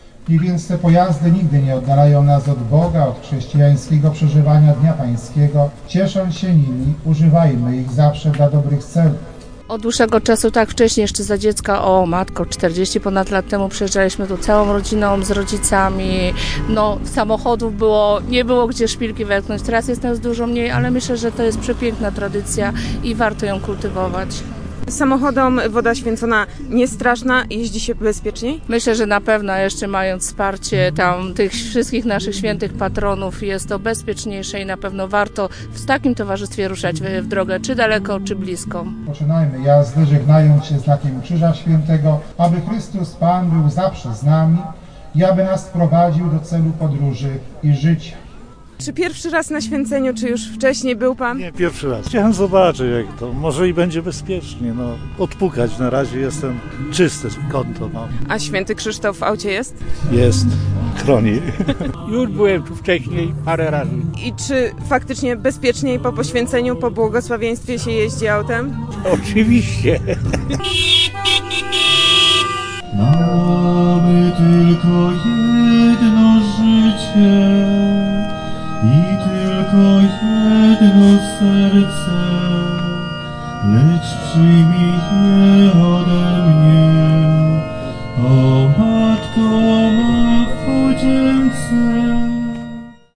Zaduma i modlitwa mieszała się rykiem silników i dźwiękiem klaksonów. W niedzielę na Górze św. Anny, w gminie Żukowice, odbyło się święcenie samochodów i motocykli.